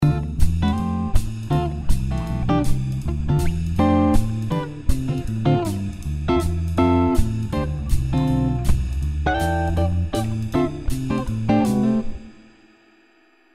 A perakkordokkal �rdekes harm�ni�kat, hangz�sokat nyerhet�nk (l�sd a fenti p�ld�kban), ill. sz�p basszusmeneteket is �rhatunk. A lenti p�ld�ban egy k�t akkordon �t�vel� menetet l�thatunk. Figyelj�k meg k�l�n a basszus sz�lam�nak ereszked�s�t!